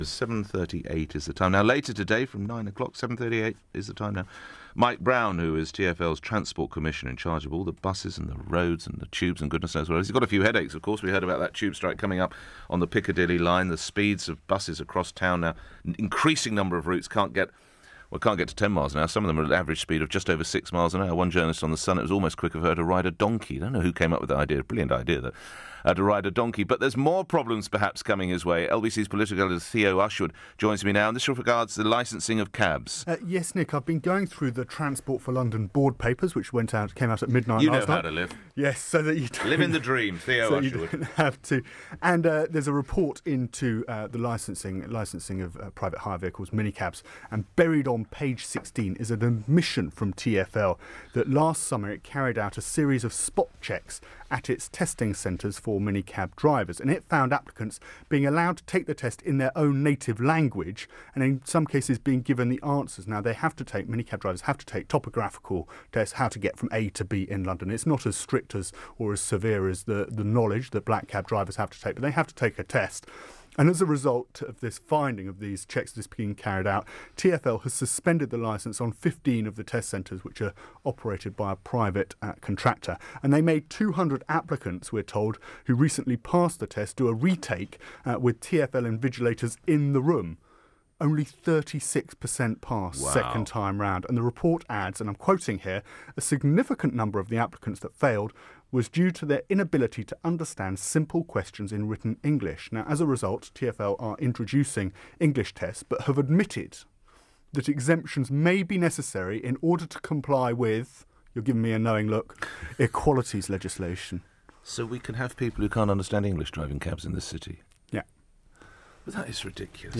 Report on minicab drivers being handed the answers to tests on how to get around London.